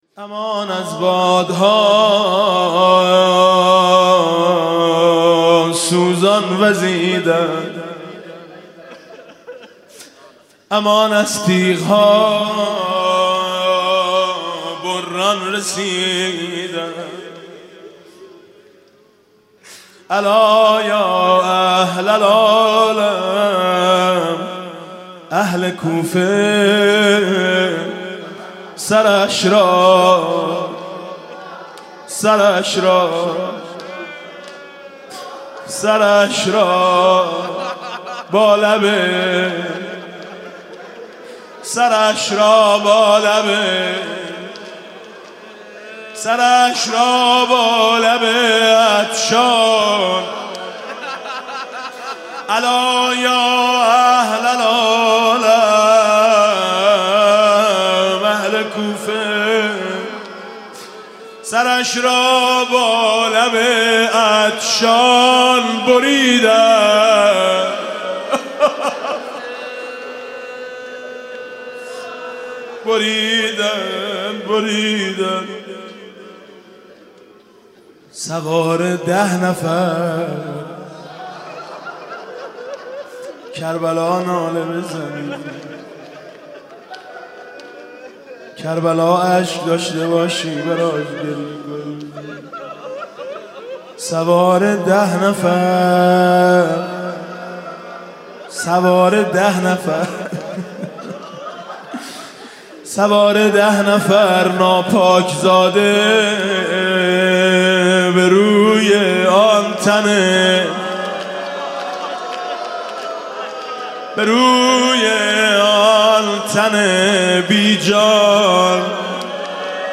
شب پنجم رمضان 96 - هیئت شهدای گمنام - روضه - سرش را با لب عطشان بریدند
روضه